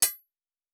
Blacksmith 06.wav